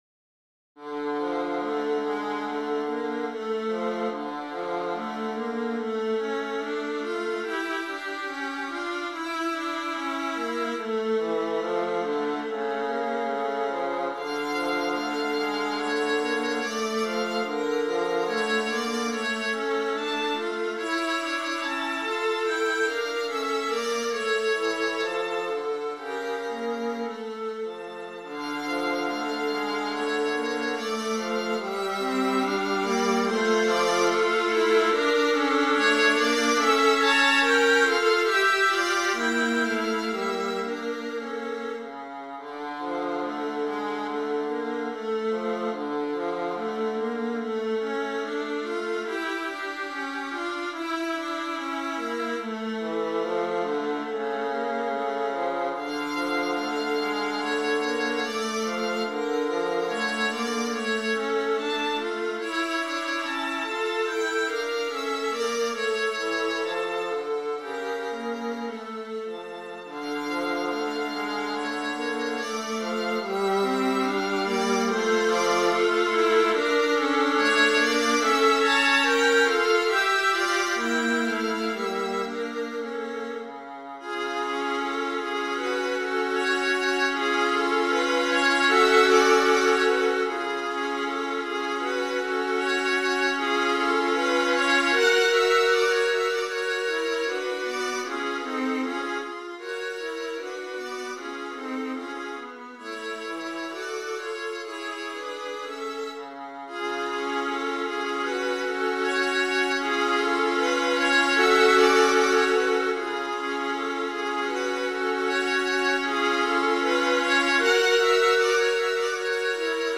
Here’s a rather dreamy and introspective piece of music.